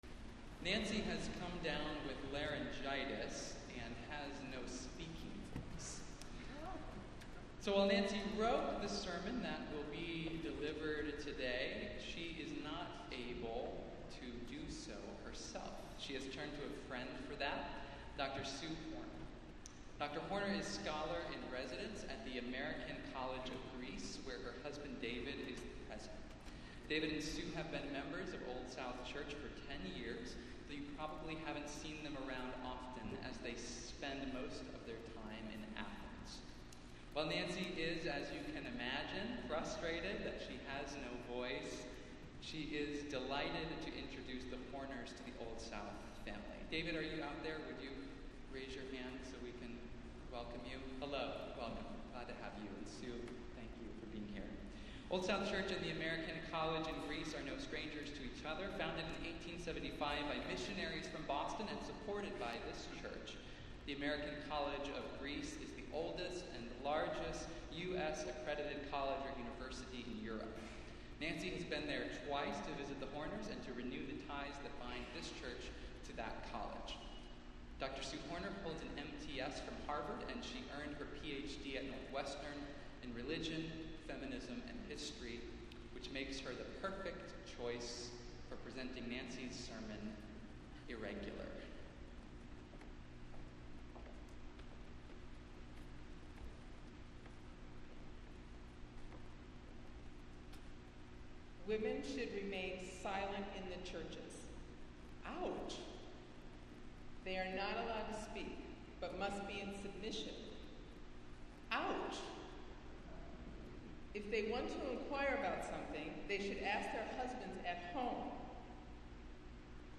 Festival Worship - Tenth Sunday after Pentecost